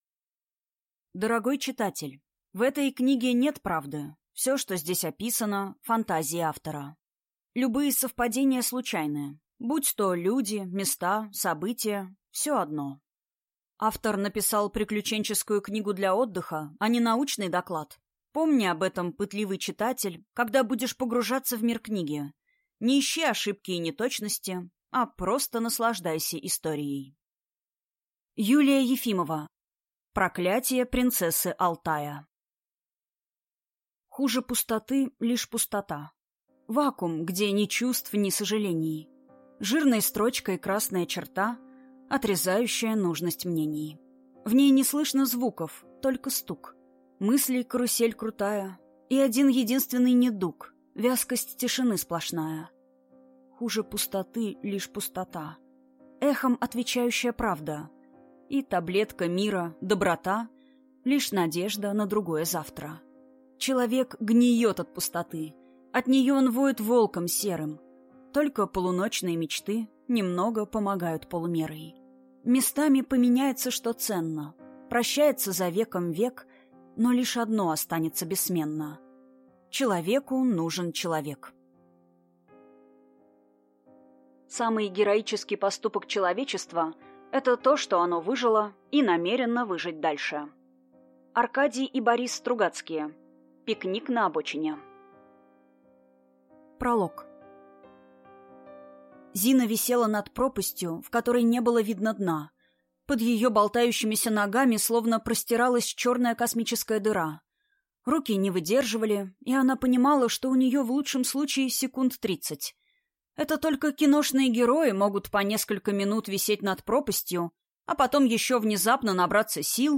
Аудиокнига Проклятие принцессы Алтая | Библиотека аудиокниг